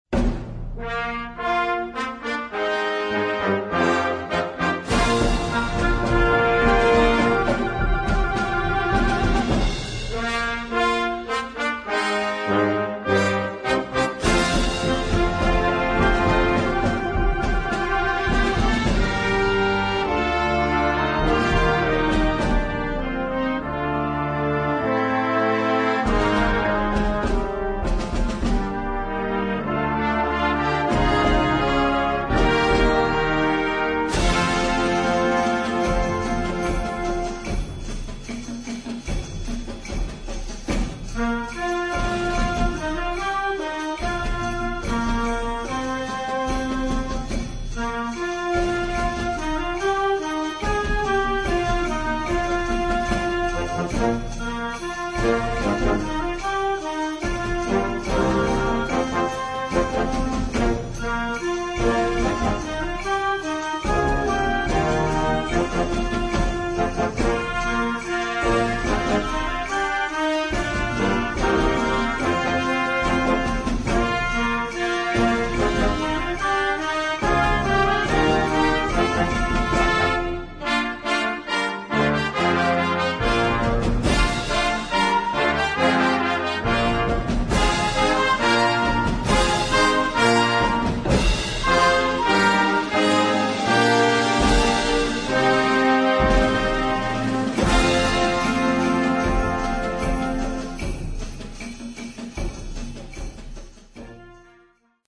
Partitions pour orchestre d'harmonie des jeunes.